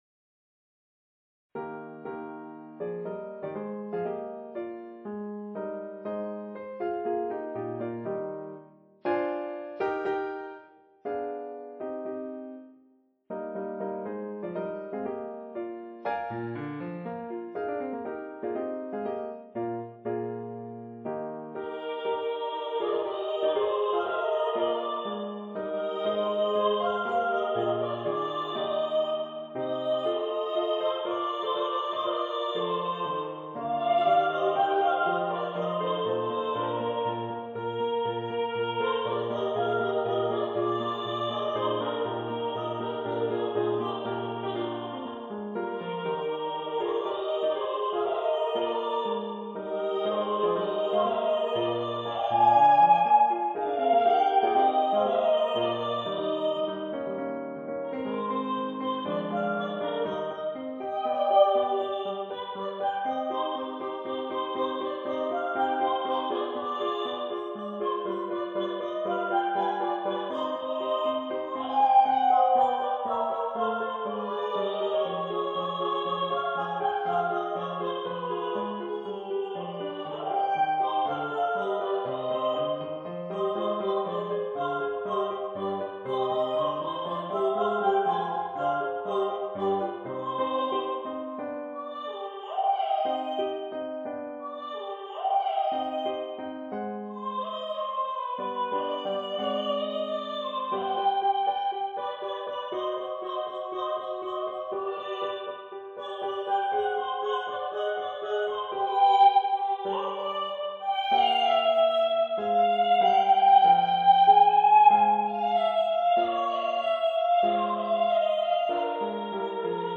vocal mock-up